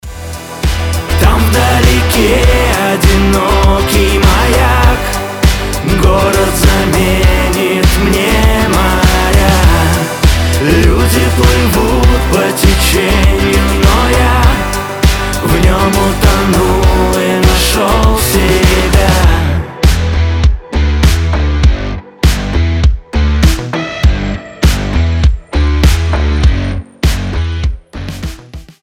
• Качество: 320, Stereo
душевные